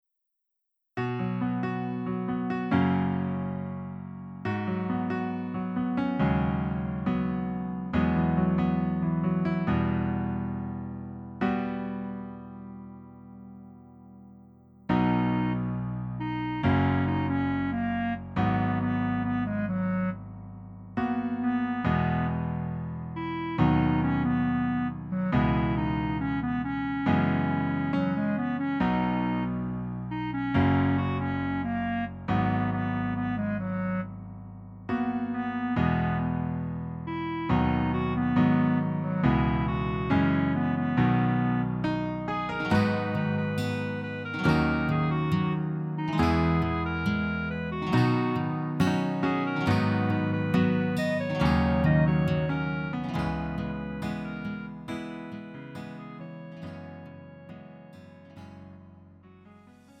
음정 -1키 3:38
장르 가요 구분 Lite MR
Lite MR은 저렴한 가격에 간단한 연습이나 취미용으로 활용할 수 있는 가벼운 반주입니다.